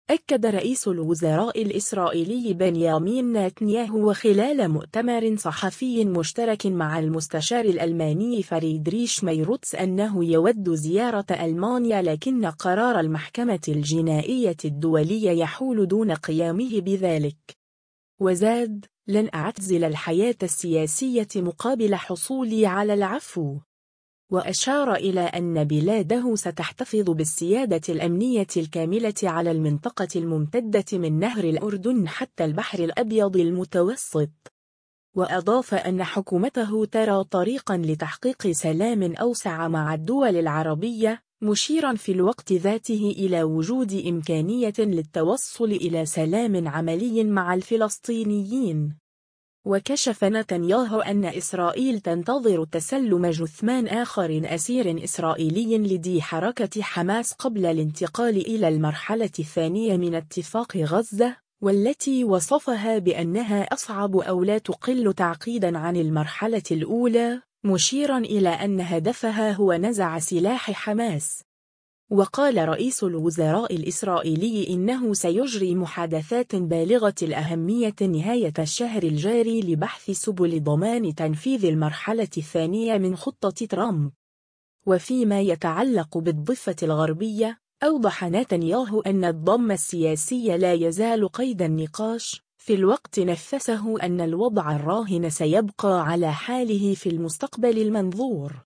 أكّد رئيس الوزراء الإسرائيلي بنيامين ناتنياهو خلال مؤتمر صحفي مشترك مع المستشار الألماني فريدريش ميرتس أنّه يود زيارة ألمانيا لكن قرار المحكمة الجنائية الدولية يحول دون قيامه بذلك.